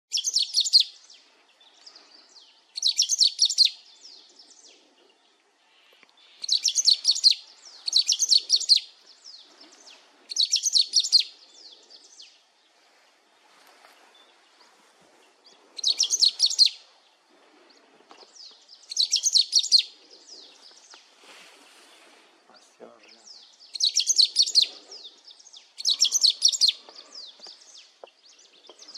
Pepitero de Corbata (Saltatricula atricollis)
Nombre en inglés: Black-throated Saltator
Localidad o área protegida: Reserva Natural del Bosque Mbaracayú
Condición: Silvestre
Certeza: Vocalización Grabada
saltatricula-atricollis.mp3